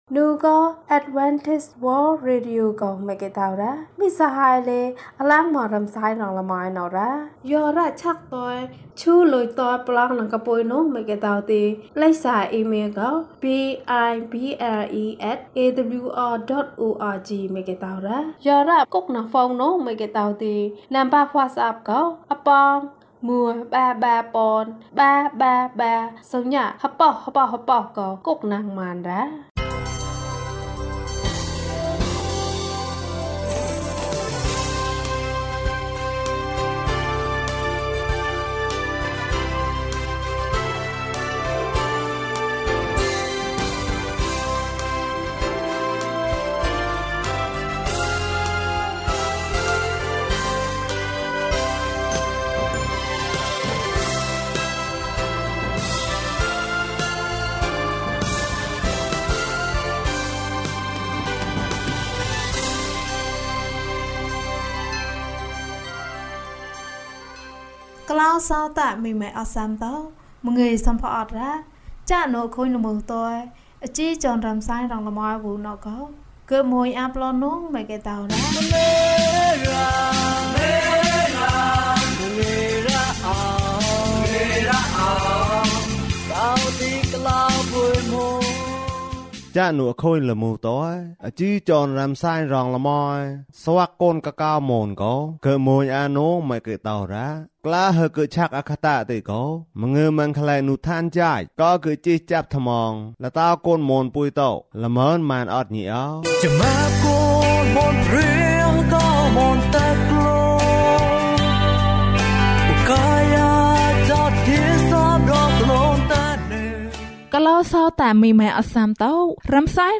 ငါ့ဘဝရဲ့အလင်းရောင်။၀၁ ကျန်းမာခြင်းအကြောင်းအရာ။ ဓမ္မသီချင်း။ တရားဒေသနာ။